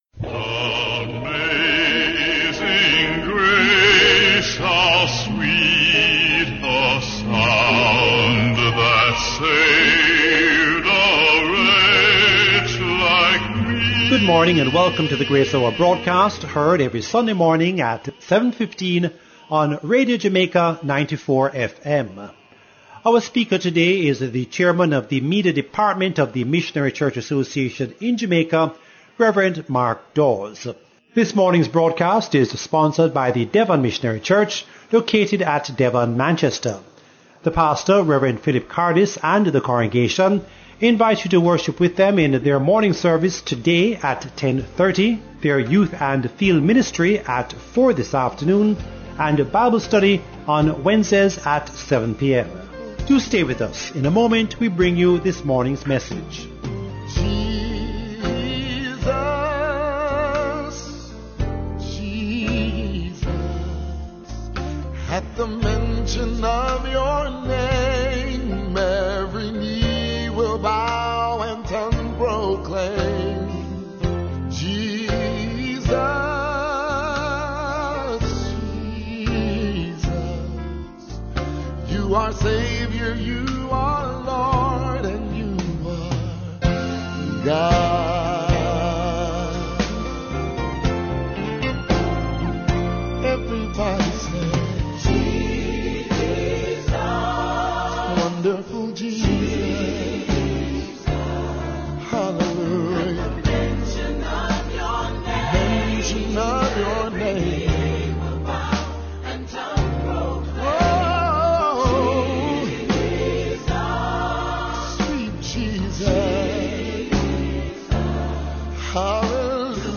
Grace Hour Broadcast 2 August 2020